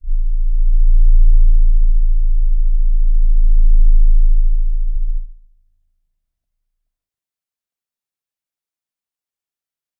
G_Crystal-A0-f.wav